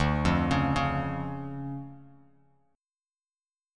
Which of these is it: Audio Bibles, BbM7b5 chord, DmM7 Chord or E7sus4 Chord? DmM7 Chord